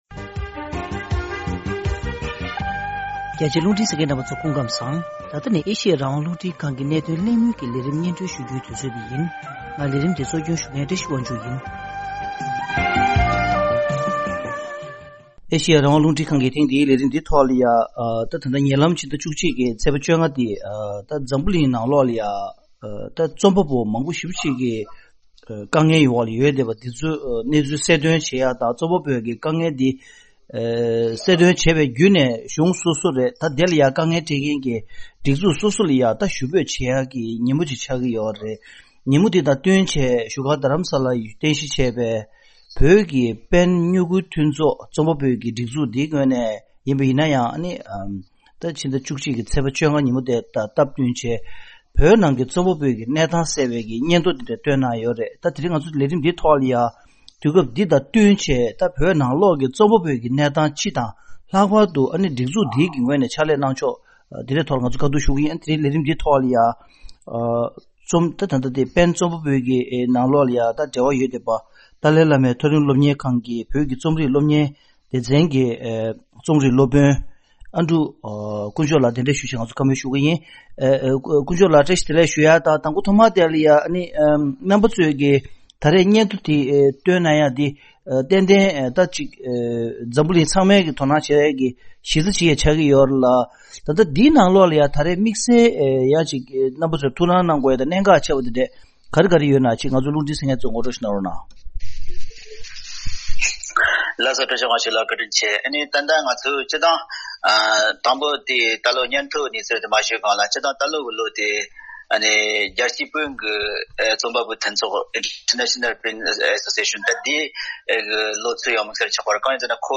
དེ་ཉིན་པེན་བོད་ཀྱི་རྩོམ་པ་པོའི་མཐུན་ཚོགས་ངོས་ནས་བོད་ཀྱི་བཙོན་འོག་རྩོམ་པ་པོའི་དམིགས་བསལ་སྙན་ཐོ་འདོན་སྤེལ་གནང་ཡོད་པ་རེད། ཐེངས་འདིའི་གནད་དོན་གླེང་མོལ་གྱི་ལས་རིམ་ནང་།